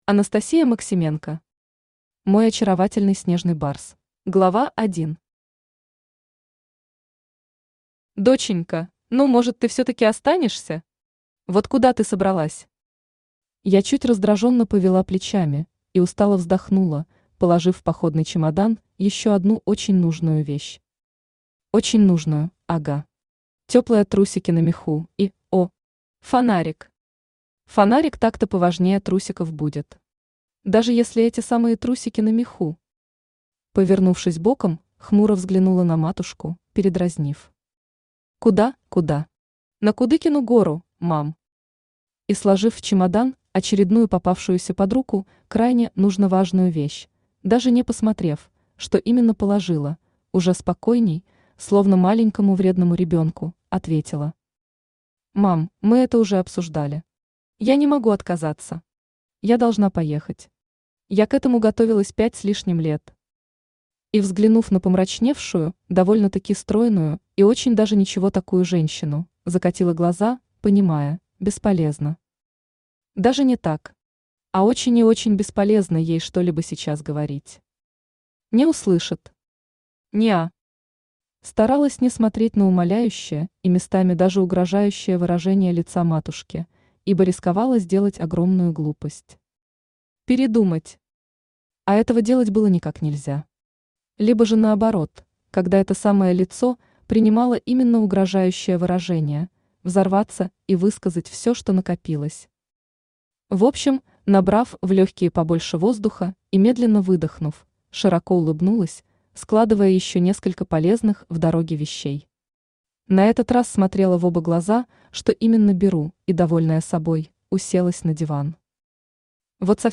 Аудиокнига Мой очаровательный снежный барс | Библиотека аудиокниг